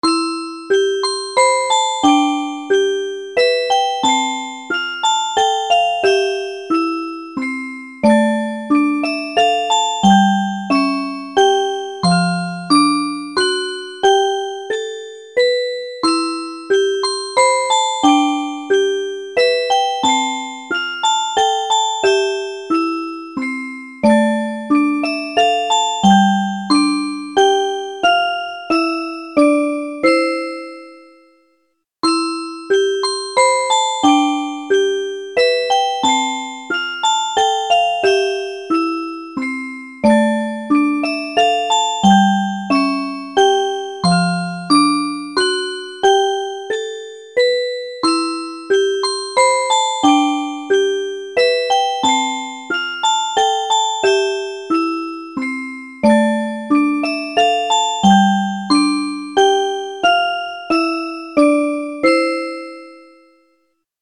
～～明るい・やさしい曲～～